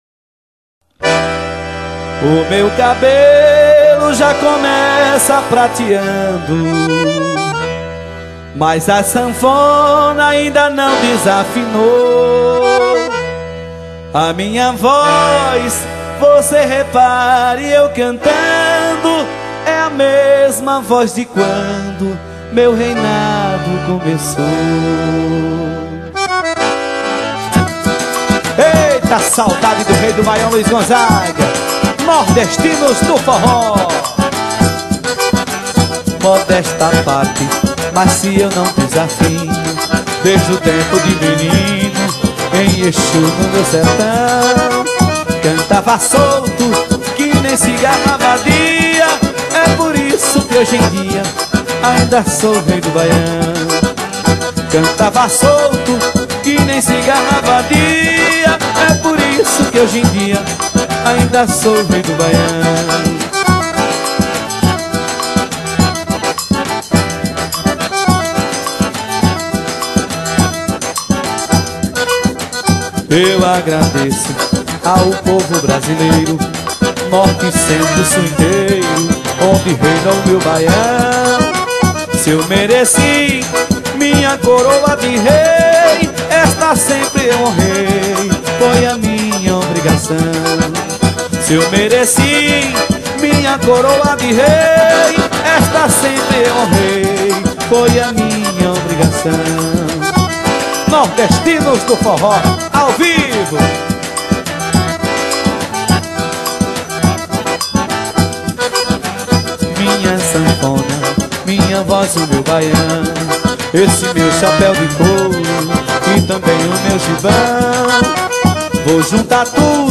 2024-12-22 10:48:12 Gênero: Forró Views